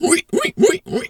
pgs/Assets/Audio/Animal_Impersonations/pig_2_hog_seq_02.wav at master
pig_2_hog_seq_02.wav